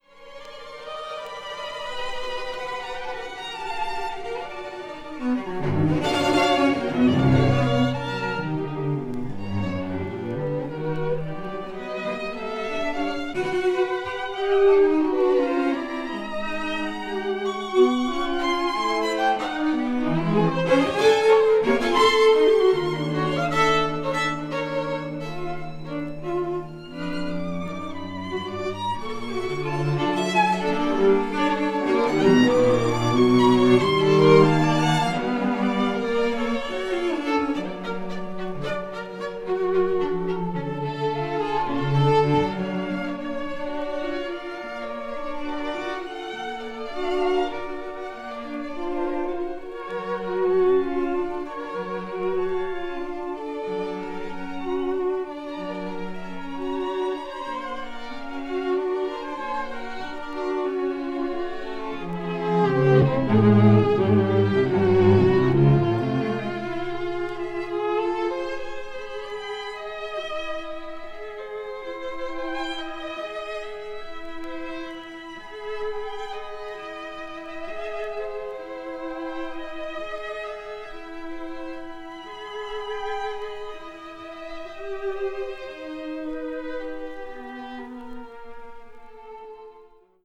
media : EX/EX(わずかにチリノイズが入る箇所あり)
20th century   chamber music   contemporary   post modern